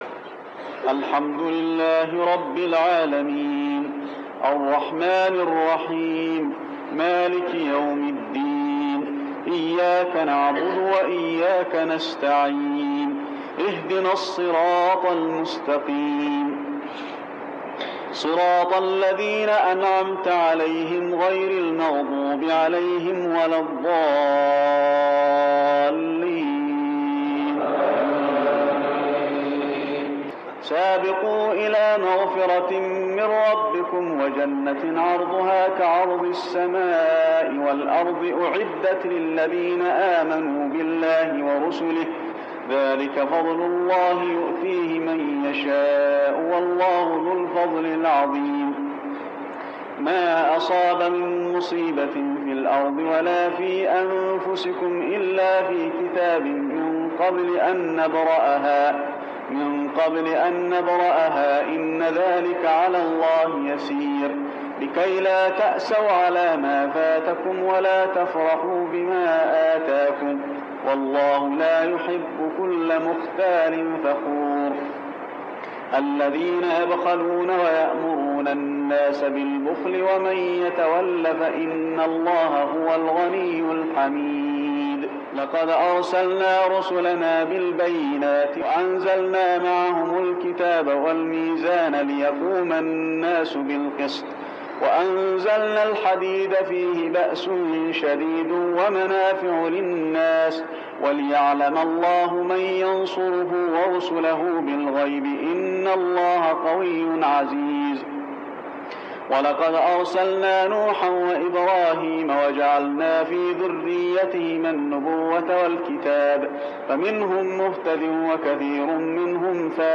جزء من صلاة التراويح عام 1400هـ سورة الحديد 21-28 | Tarawih Prayer Surah Al-Hadid > تراويح الحرم النبوي عام 1400 🕌 > التراويح - تلاوات الحرمين